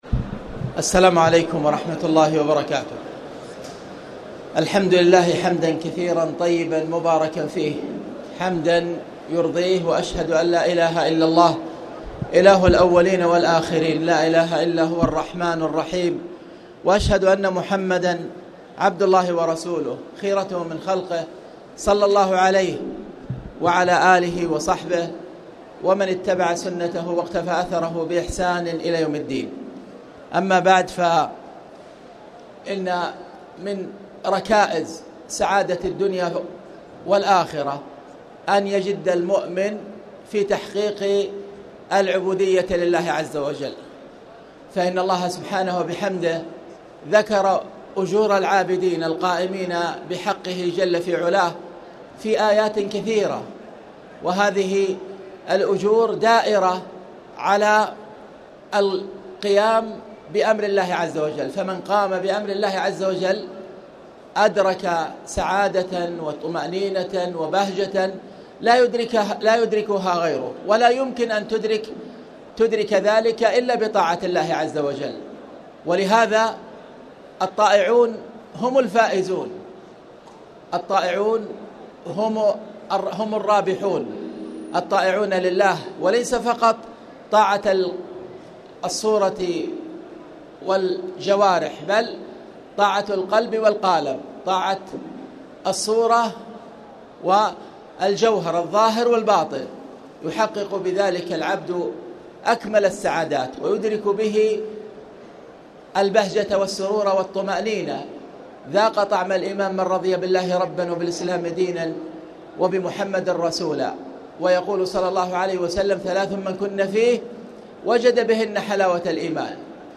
تاريخ النشر ٢٥ رمضان ١٤٣٨ هـ المكان: المسجد الحرام الشيخ